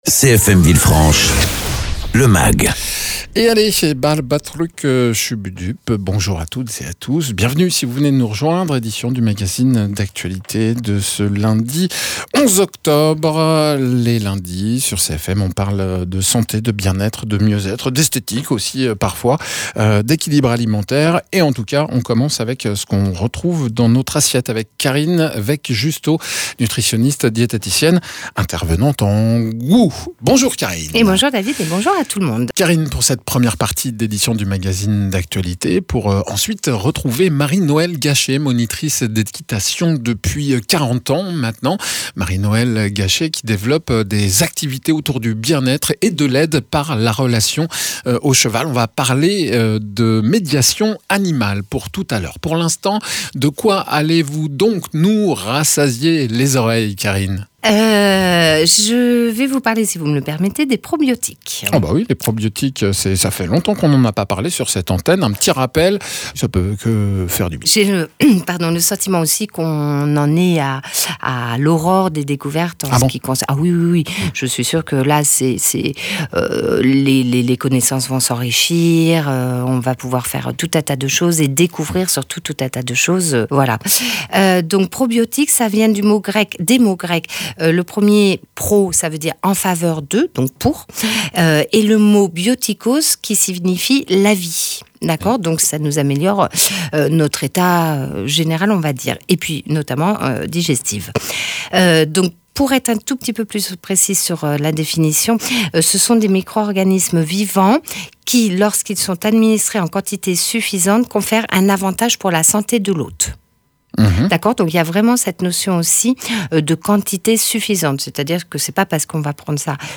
nutritionniste diététicien